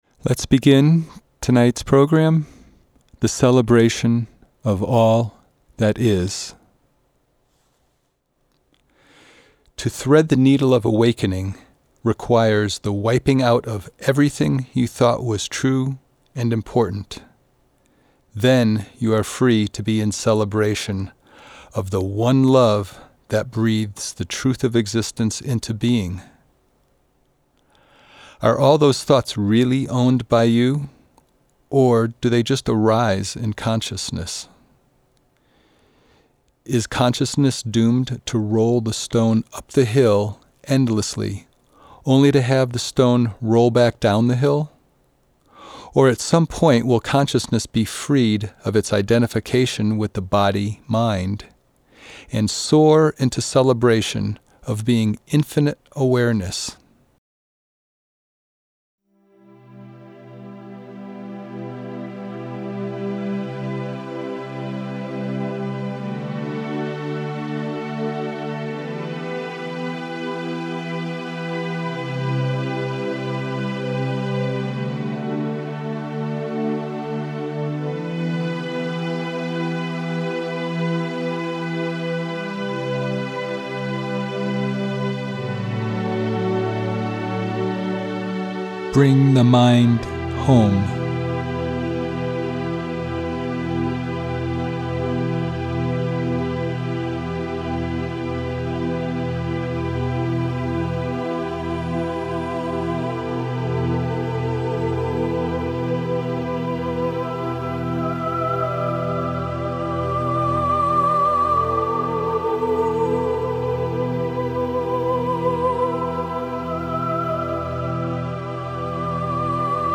transformational healing music